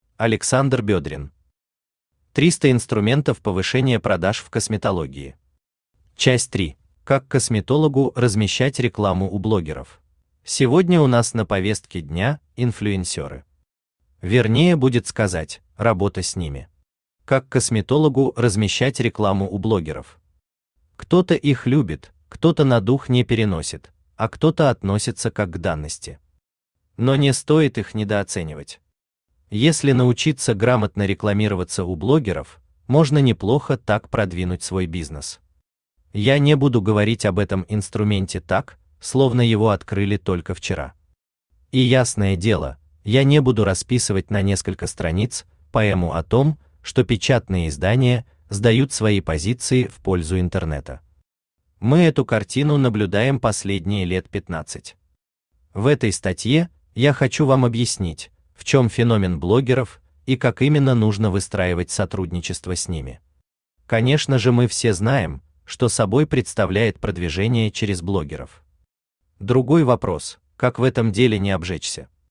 Аудиокнига 300 инструментов повышения продаж в косметологии. Часть 3 | Библиотека аудиокниг
Aудиокнига 300 инструментов повышения продаж в косметологии. Часть 3 Автор Александр Владиславович Бедрин Читает аудиокнигу Авточтец ЛитРес. Прослушать и бесплатно скачать фрагмент аудиокниги